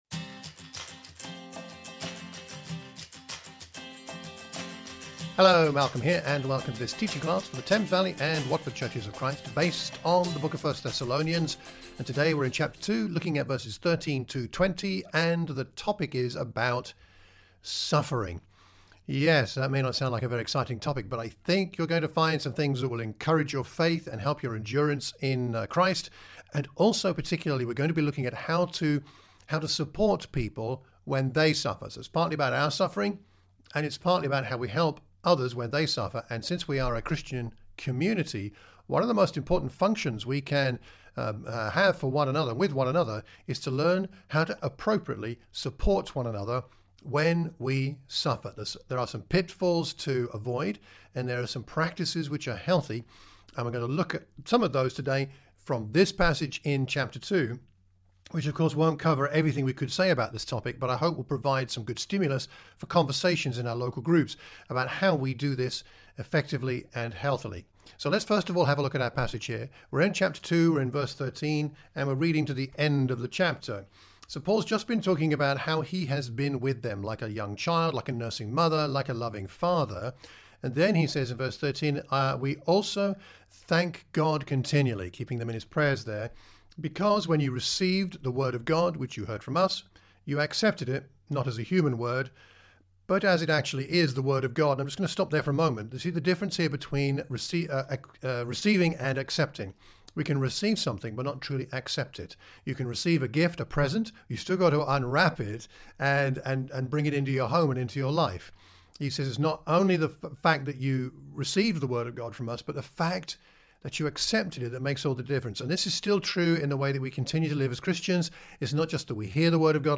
1 Thessalonians | Teaching Class - Chapter 2vv13-20 | How to Support the Suffering